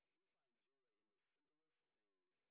sp07_train_snr20.wav